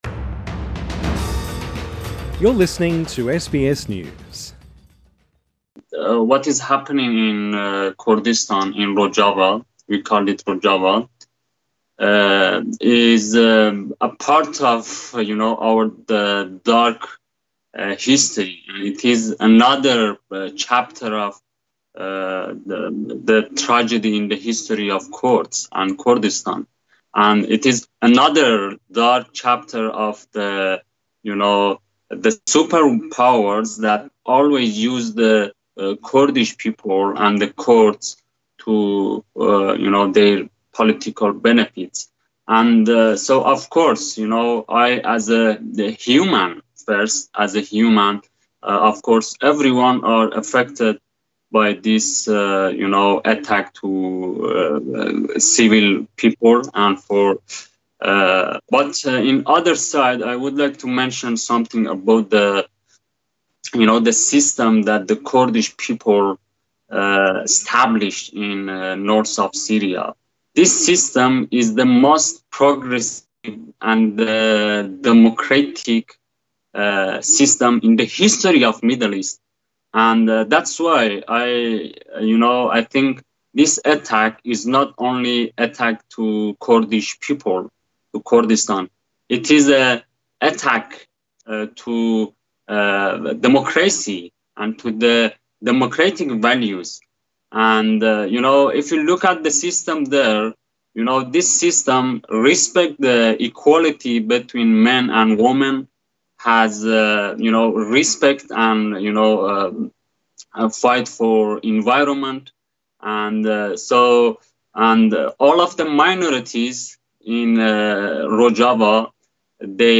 INTERVIEW: Kurdish refugee Behrouz Boochani talks to SBS about his reaction to events in northern Syria